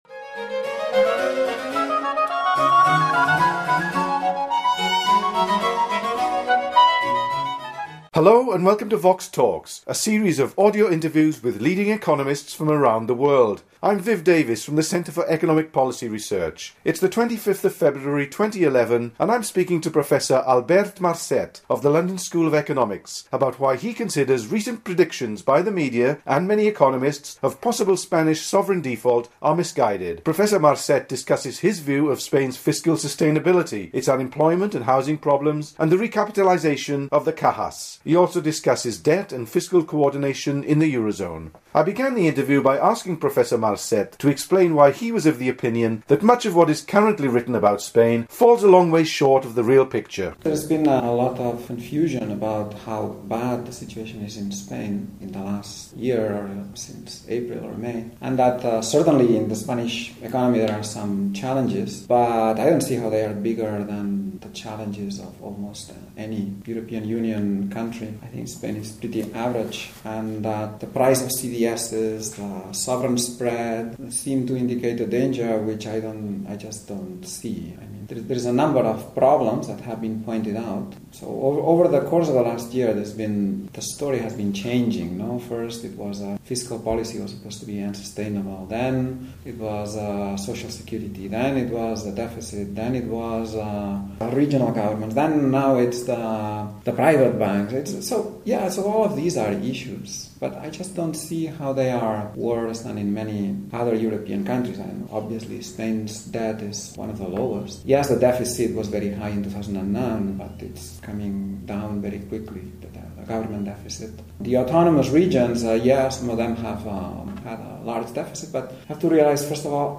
The interview was recorded in London in February 2011.